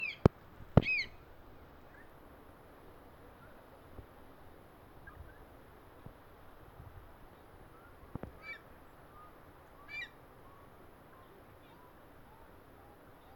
Black-chested Buzzard-Eagle (Geranoaetus melanoleucus)
Observe a los adultos y al inmaduro volando juntos vocalizando constantemente y pasándose una presa que los adultos soltaban desde lo alto y el pichón atrapaba, luego el inmaduro quedo solo en un árbol vocalizando (grabaciones)
Life Stage: Several
Location or protected area: Parque Natural Aguas de Ramón
Condition: Wild
Certainty: Observed, Recorded vocal